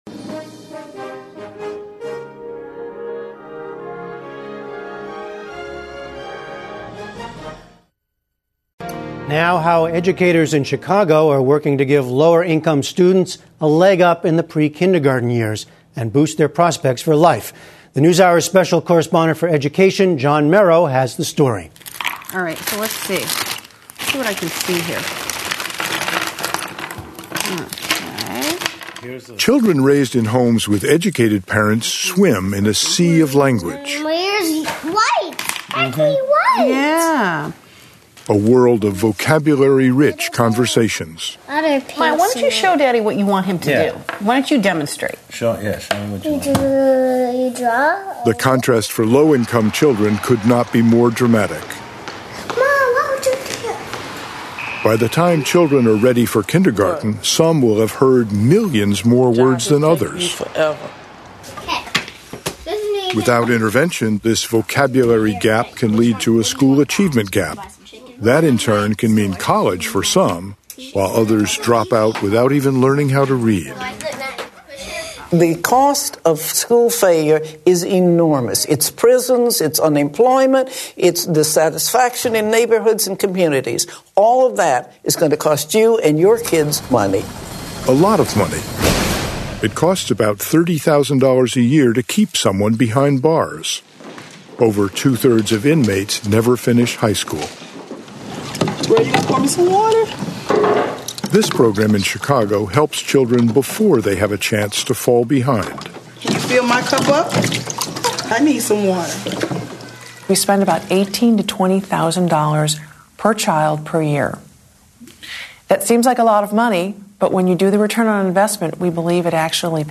英语访谈节目:芝加哥幼儿学前教育计划 让孩子不输在起跑线上